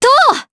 Leo-Vox_Attack3_jp.wav